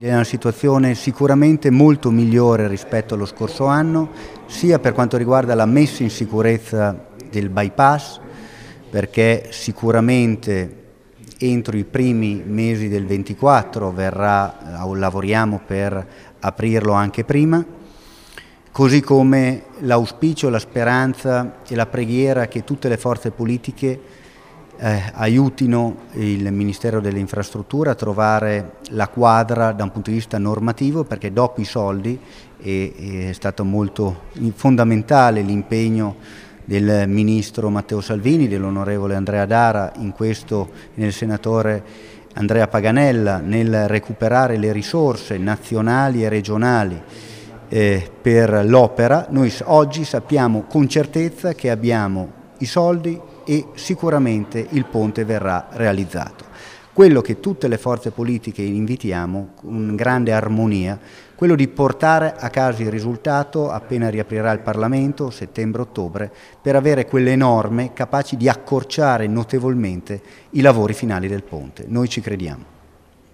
Ecco le dichiarazioni raccolte nella giornata dell’incontro:
Carlo Bottani, presidente della Provincia
Ponte-presidente-carlo-bottani.mp3